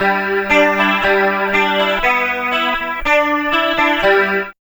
78 GTR 3  -L.wav